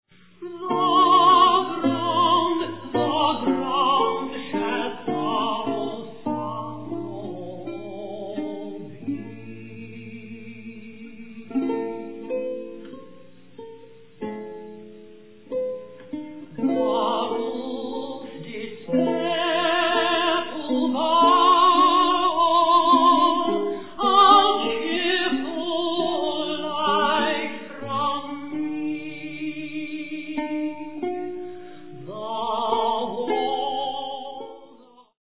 for voice, lute & bass viol